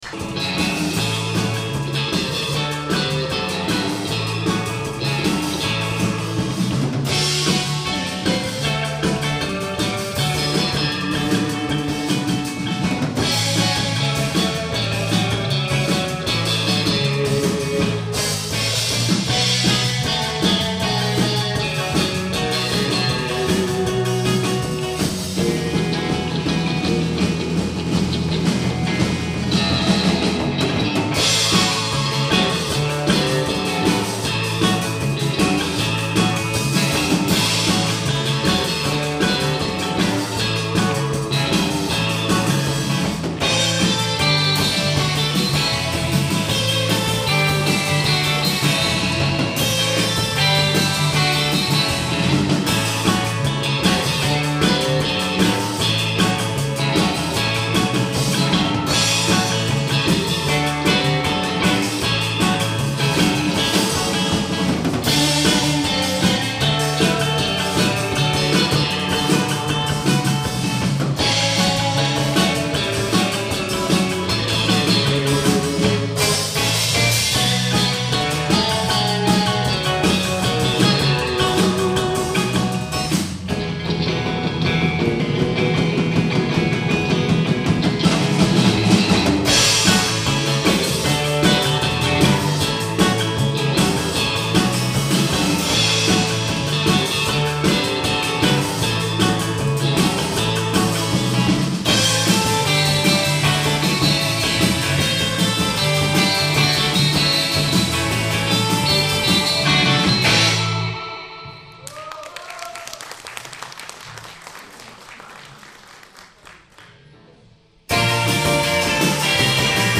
Poniżej fragmenty koncertu
Duet voc-instrument.